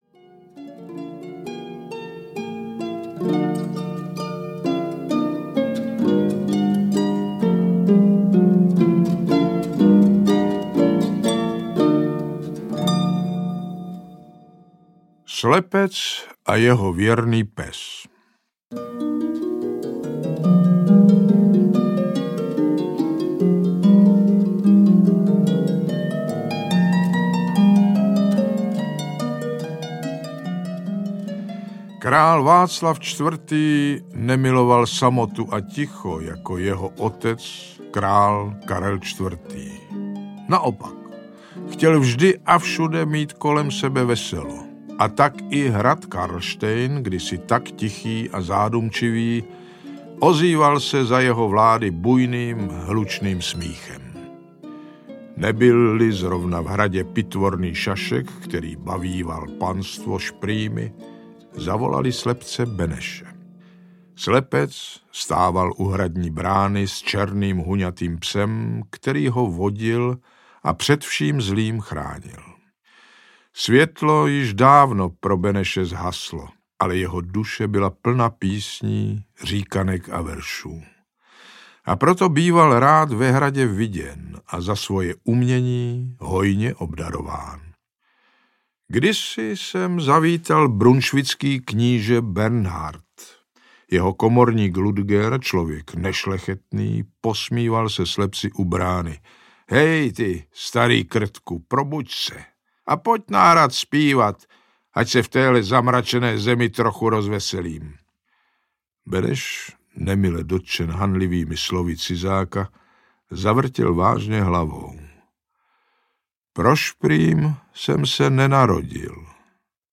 33 pověstí o českých hradech a zámcích audiokniha
Ukázka z knihy
• InterpretJan Kanyza
33-povesti-o-ceskych-hradech-a-zamcich-audiokniha